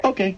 morning radio show